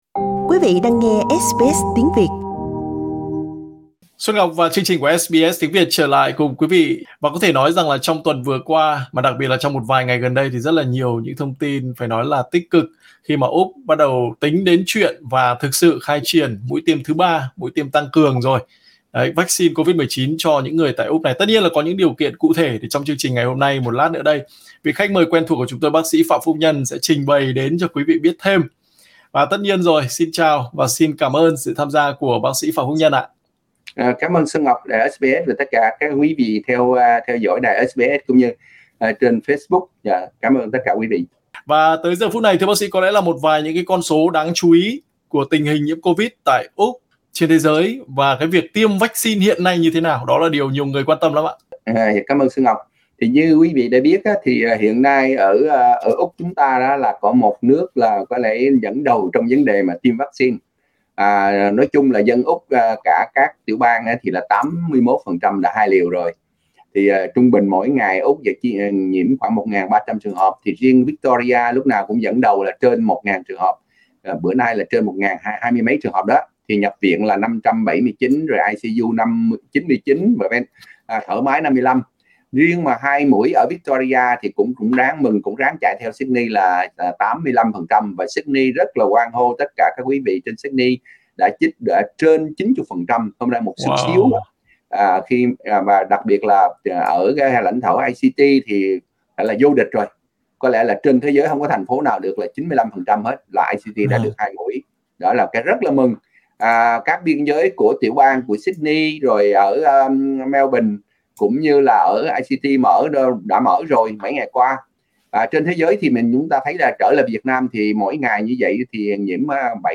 Trong cuộc phỏng vấn với SBS Tiếng Việt